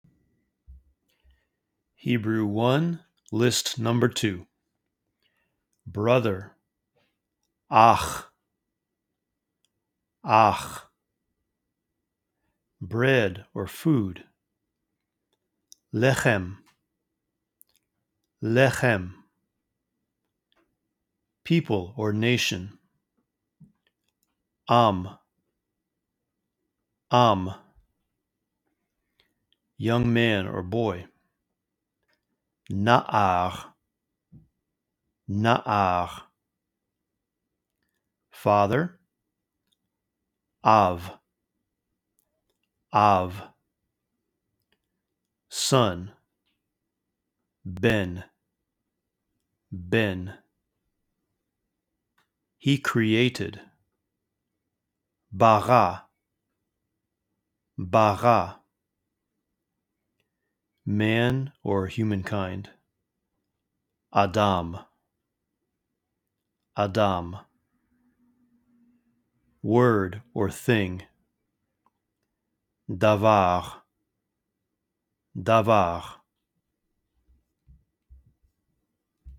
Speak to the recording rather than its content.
List 2 vocabulary words to help you memorize. You’ll hear each word in English, then twice in Hebrew. Use the pauses between words to repeat the Hebrew words.